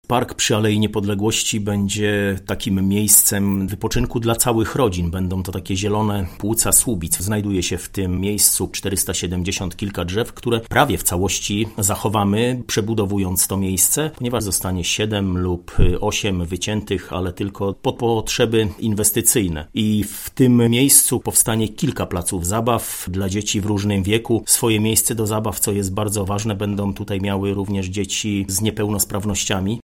’- Swoje miejsce do zabaw będą też miały dzieci niepełnosprawne – zapewnia Mariusz Olejniczak, burmistrz Słubic.